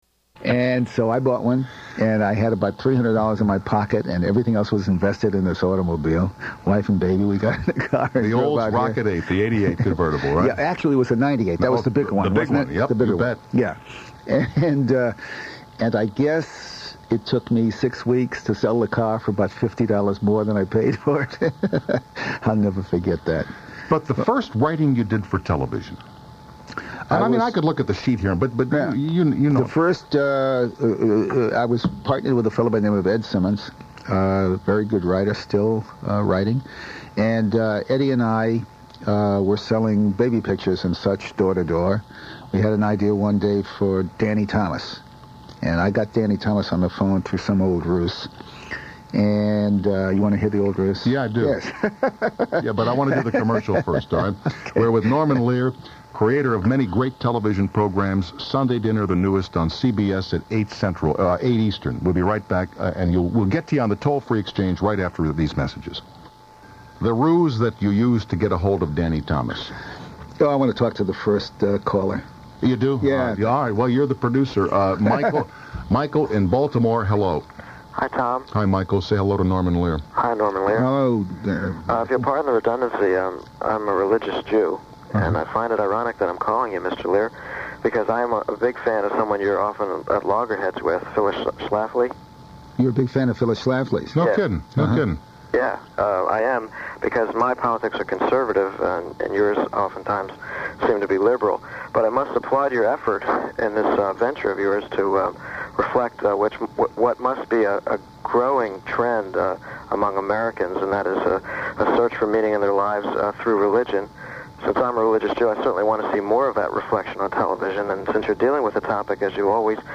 Tom Snyder falls head over heels in love with Bonnie Hunt right on the air.
Tom and Bonnie recall their respective strict Catholic upbringings, and Tom makes a couple of remarkably intimate and revealing statements about his life and philosophy. First up is a segment with Norman Lear, followed by some “open phones” calls.
Two shows, both a little incomplete (The Lear segment is joined in progress, as is the Bonnie Hunt interview), but still a treat. Just under an hour and a half in total; commercials have been painstakingly removed.